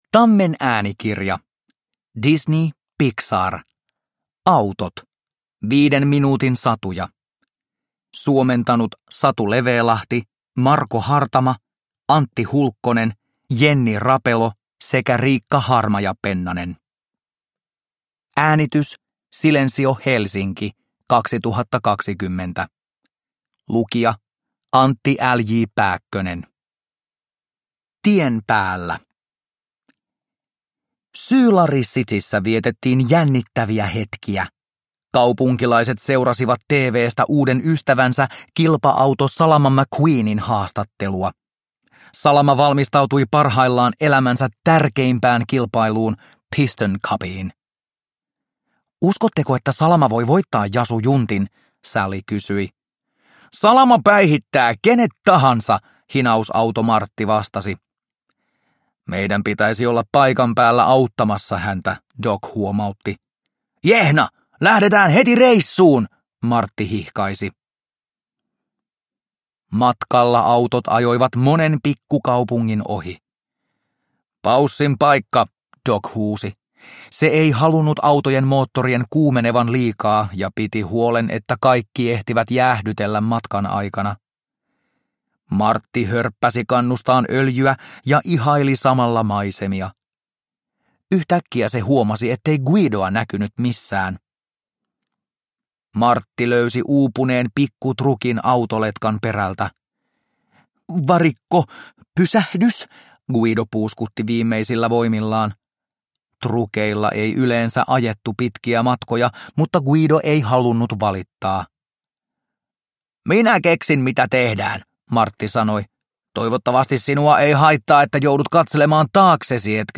Disney Pixar Autot. 5 minuutin satuja – Ljudbok – Laddas ner